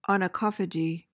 PRONUNCIATION:
(ah-nuh-KAH-fuh-je)